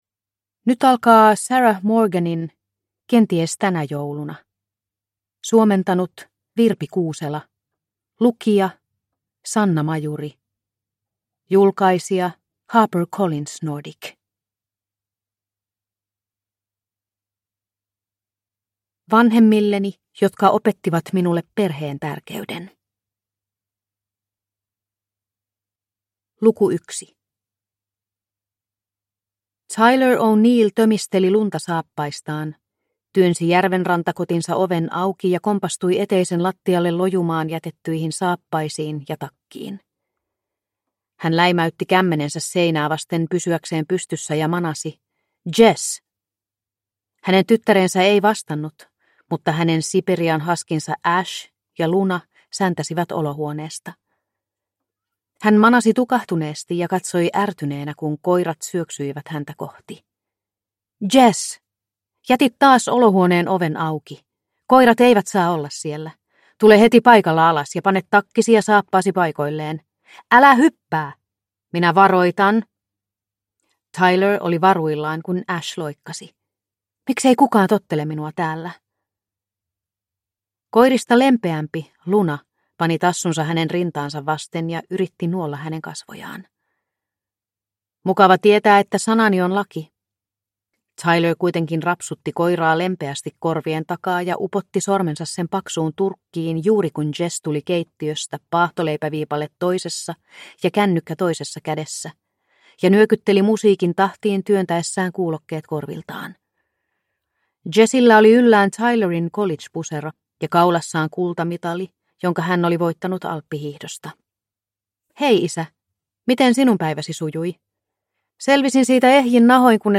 Kenties tänä jouluna – Ljudbok – Laddas ner